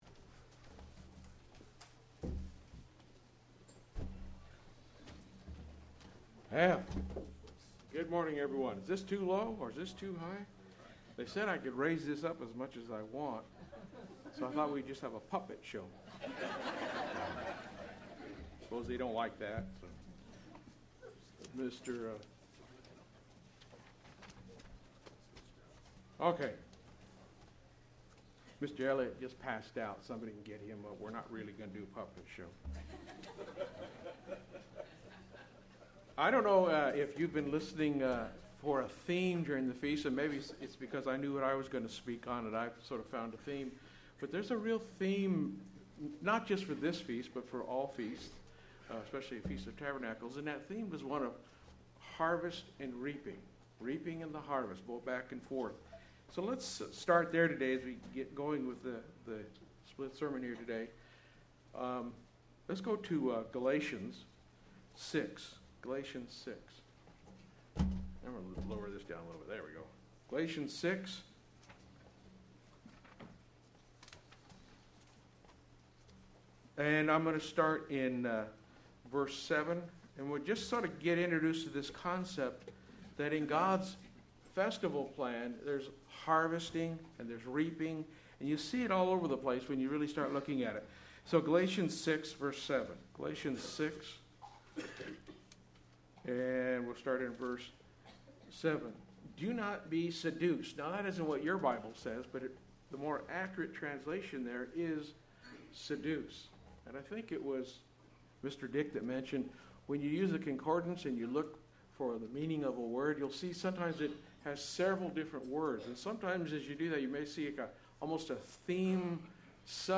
This sermon was given at the Victoria, British Columbia 2016 Feast site.